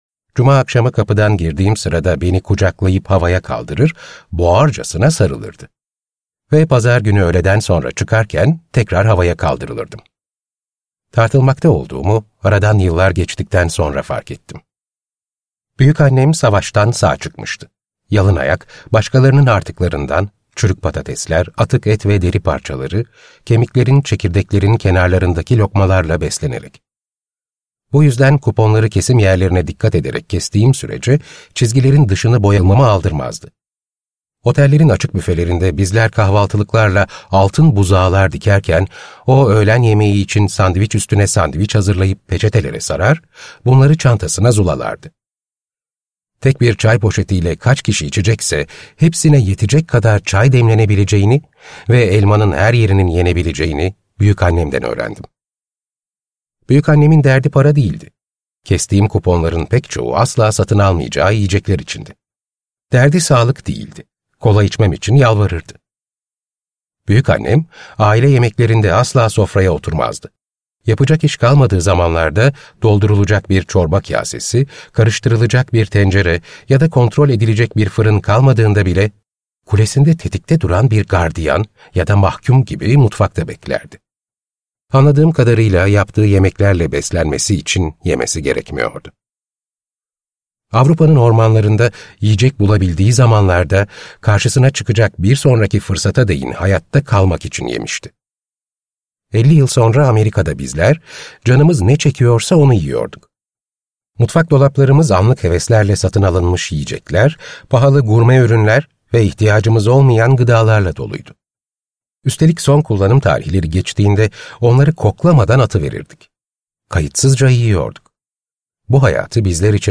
Hayvan Yemek Dinle